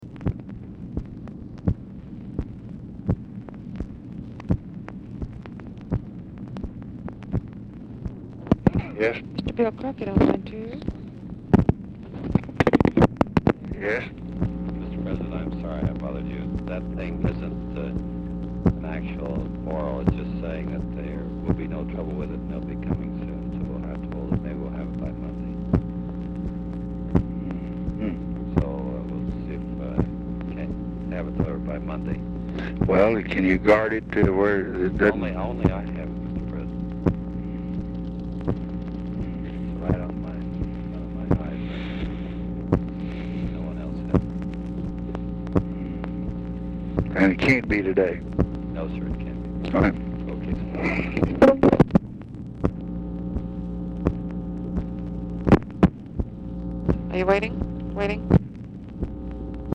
Telephone conversation
POOR SOUND QUALITY
Format Dictation belt
Location Of Speaker 1 Oval Office or unknown location